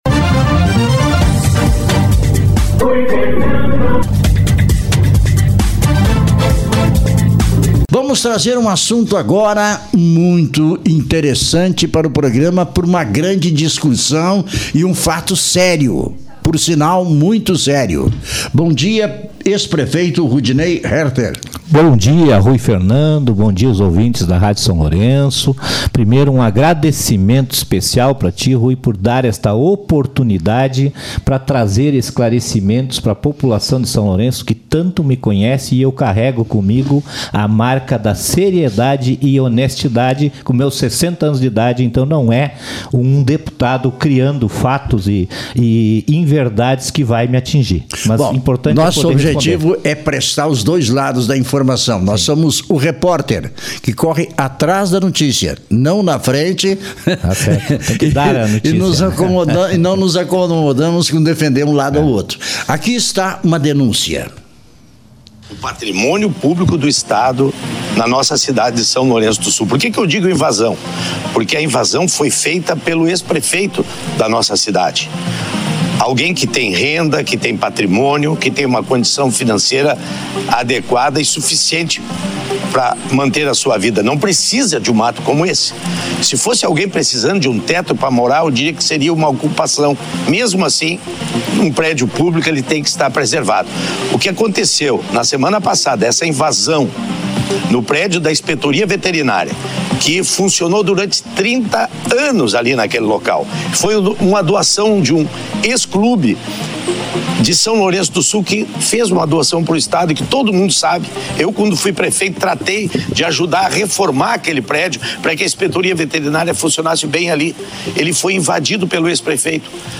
Entrevista com ex-prefeito Rudinei Härter